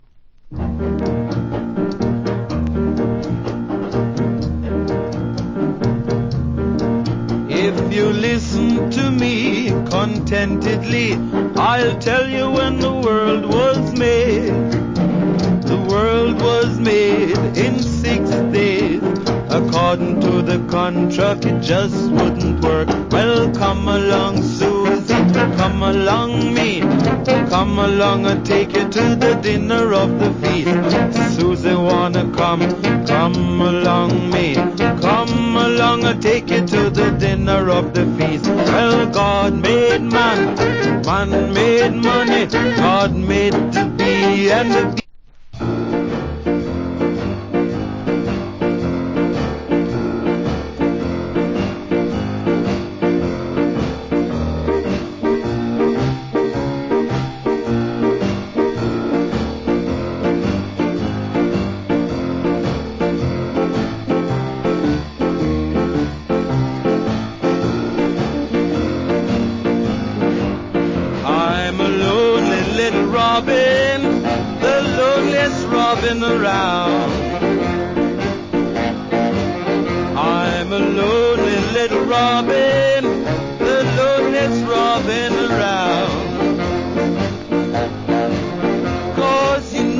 Wicked JA R&B.